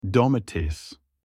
tomato-in-turkish.mp3